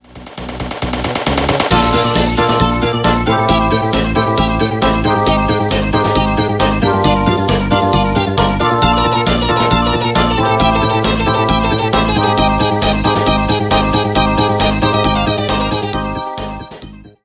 applause-gtt.mp3